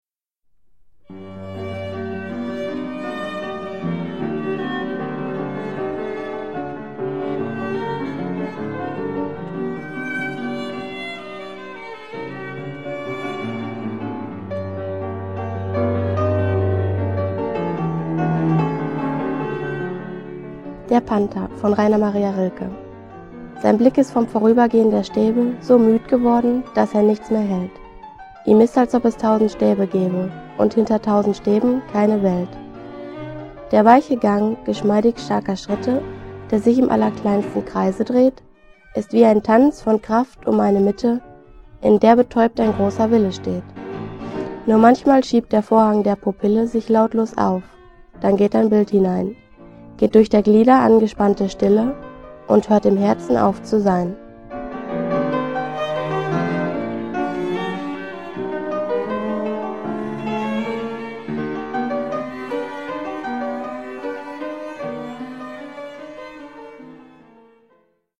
La música que l’acompanya és de Clara Schumann. Es tracta del Trio in g op 17 – Allegro moderato interpretat per Streicher Trio.